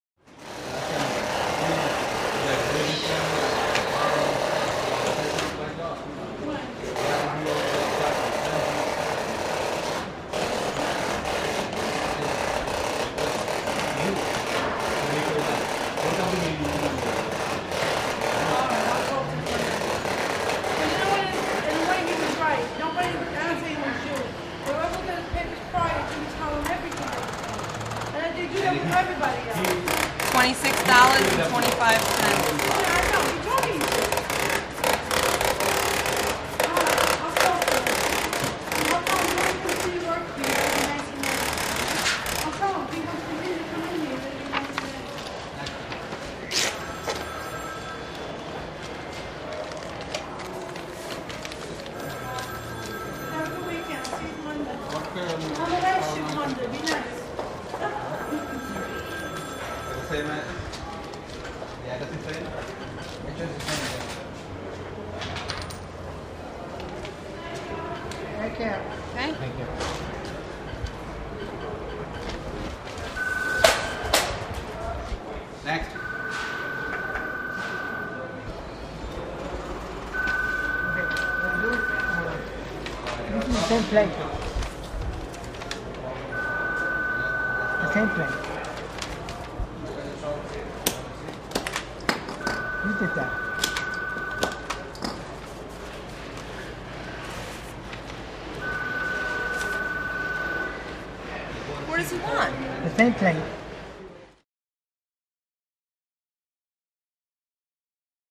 Large Bank Or Government Building Interior Next To Service Counter. Close Computer Printer Is Louder Than Track 1010-21. Some Close Walla Man Says Next? To People In Line. Phone Rings Movement,